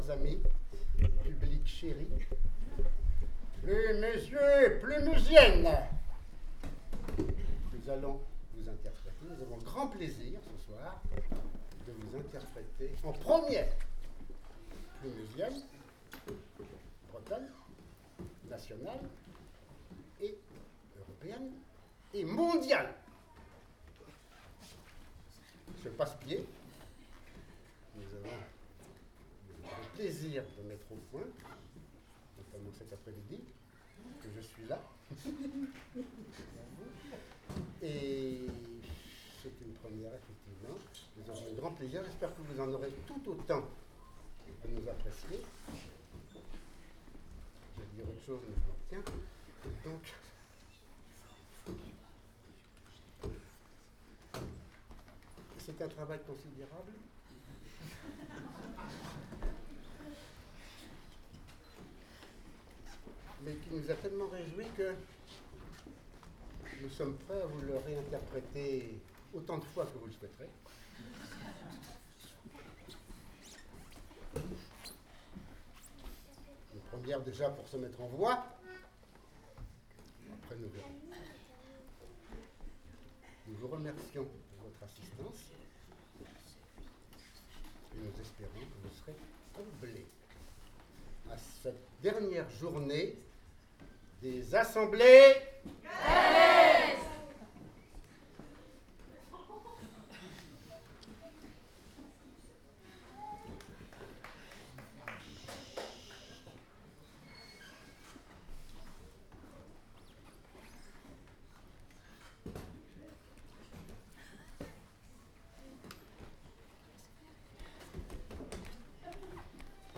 :mp3:2014:concert_stagiaires
02_passepieds.mp3